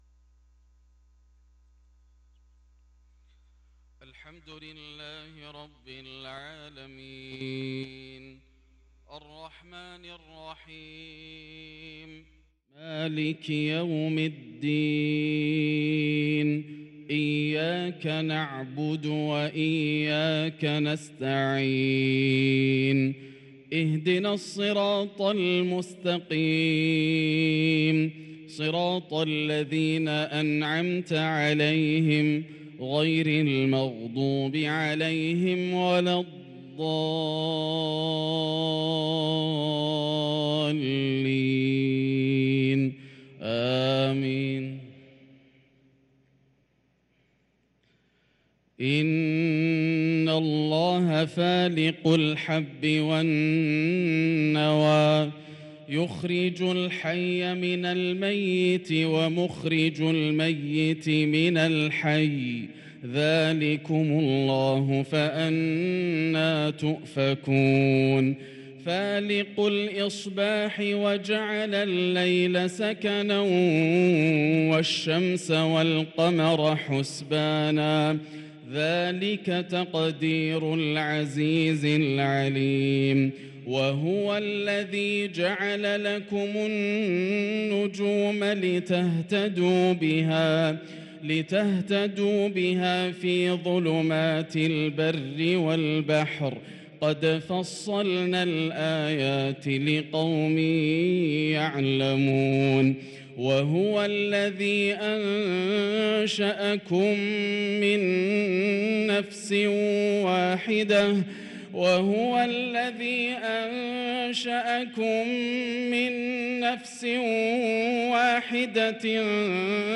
صلاة العشاء للقارئ ياسر الدوسري 4 ربيع الأول 1444 هـ
تِلَاوَات الْحَرَمَيْن .